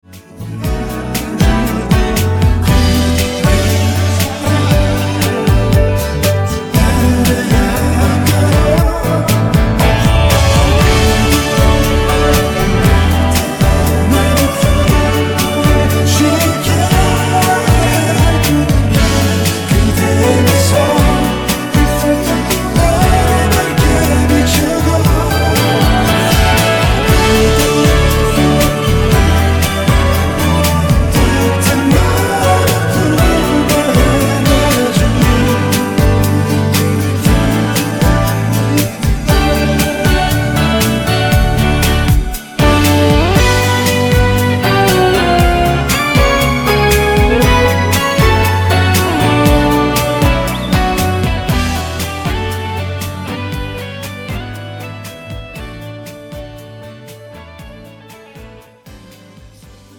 음정 코러스 -1키
장르 축가 구분 Pro MR